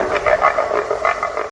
FX (5_ LAUGH).wav